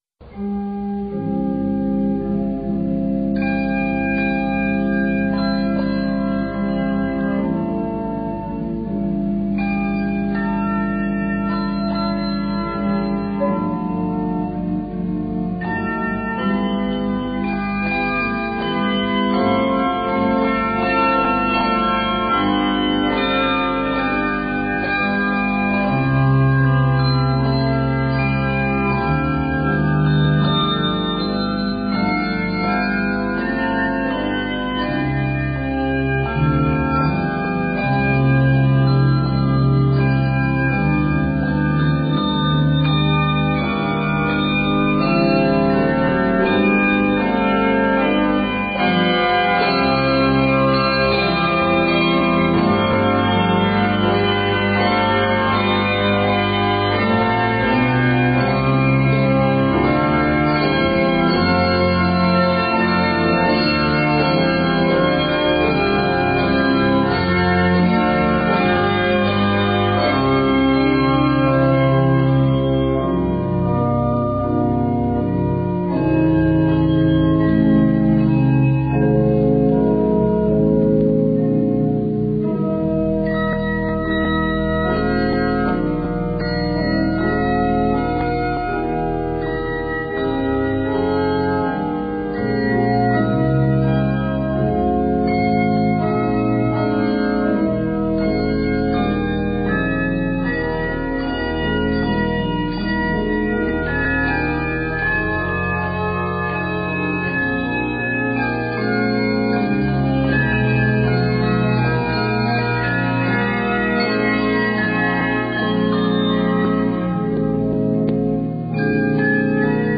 bells and organ
Octaves: 3-5